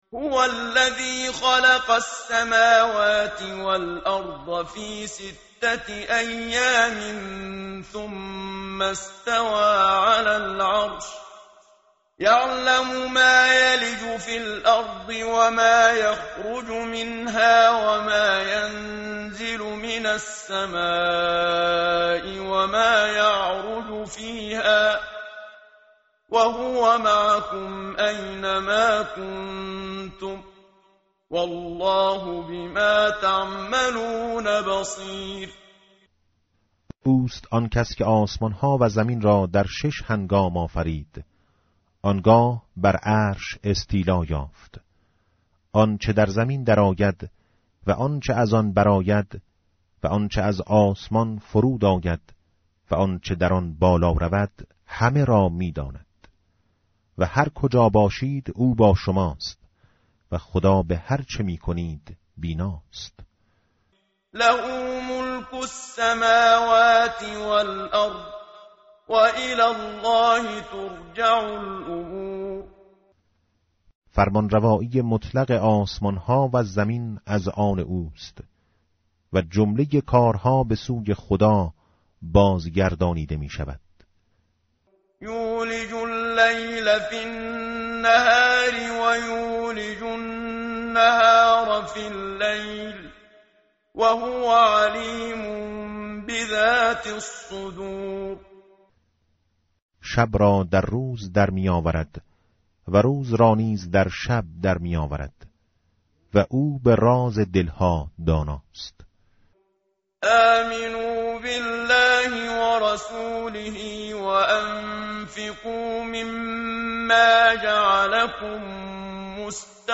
tartil_menshavi va tarjome_Page_538.mp3